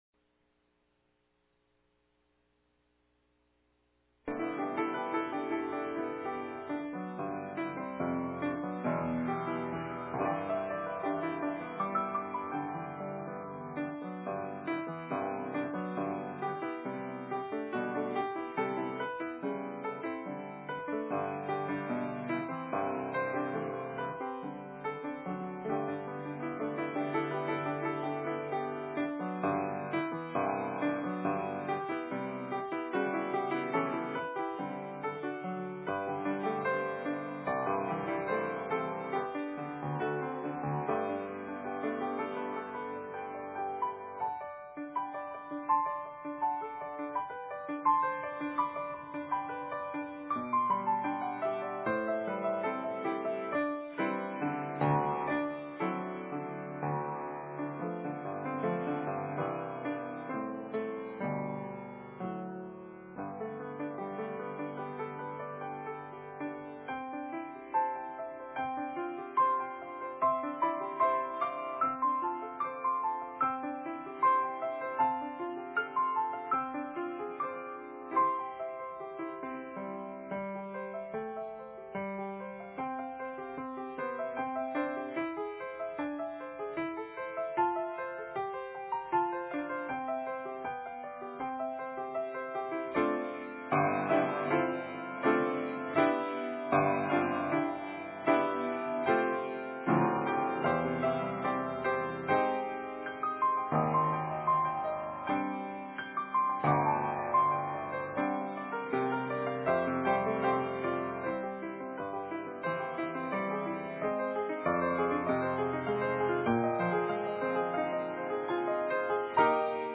No Series Passage: Galatians 4:1-7 Service Type: Morning Worship « The Ultimate Need